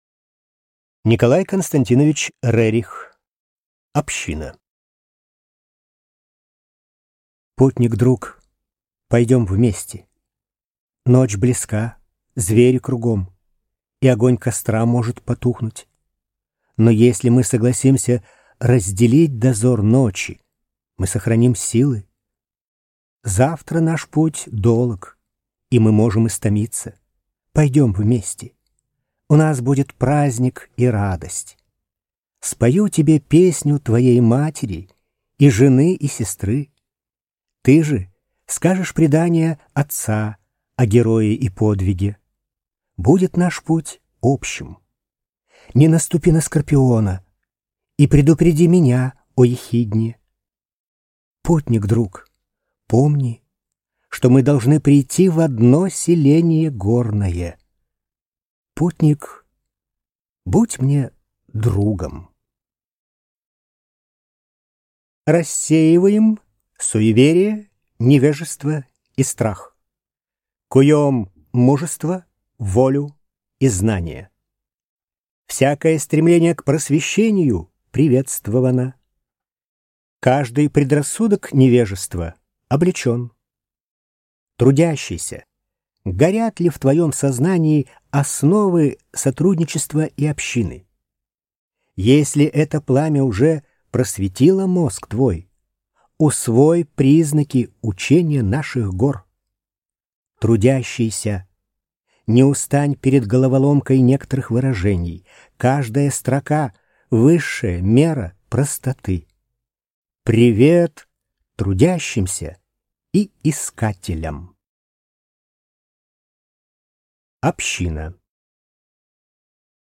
Аудиокнига Община | Библиотека аудиокниг